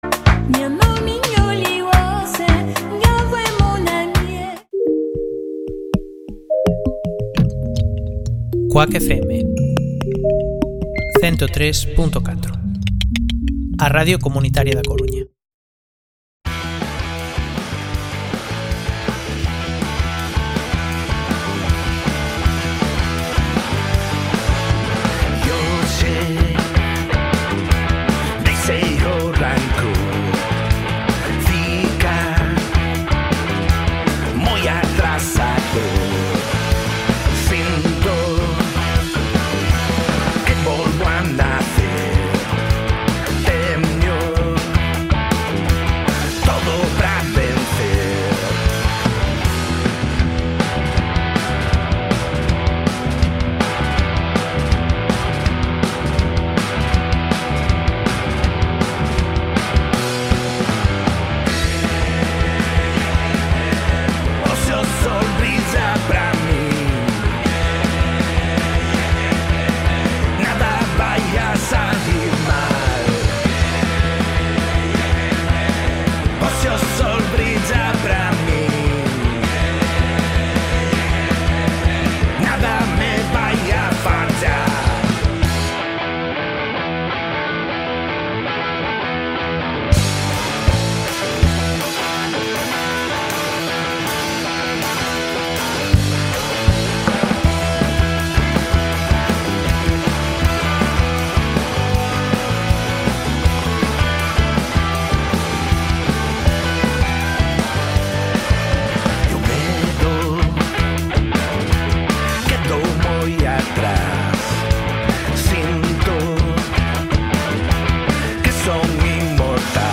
Espacio musical variopinta destacando el rock. Volando de aquí para allá dando cabida en novedades, agenda, anécdotas e incluyendo intereses audiovisuales.